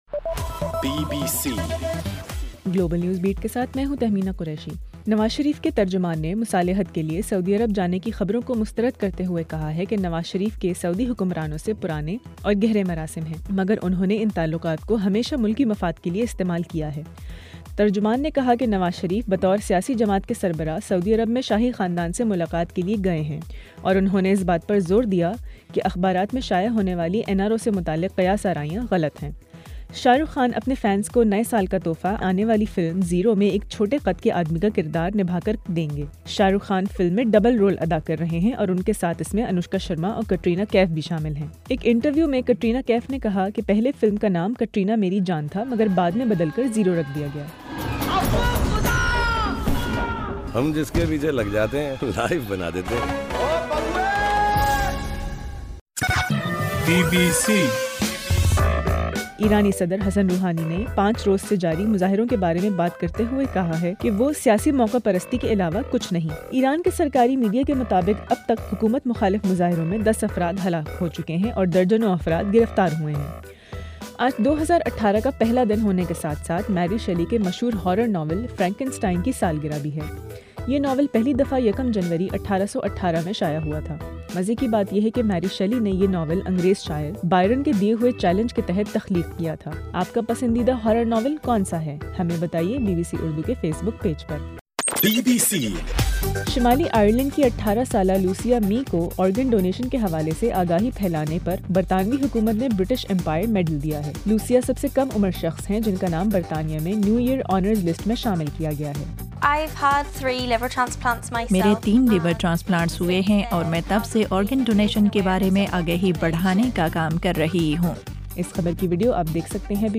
گلوبل نیوز بیٹ بُلیٹن اُردو زبان میں رات 8 بجے سے صبح 1 بجے ہرگھنٹےکے بعد اپنا اور آواز ایف ایم ریڈیو سٹیشن کے علاوہ ٹوئٹر، فیس بُک اور آڈیو بوم پر سنئیِے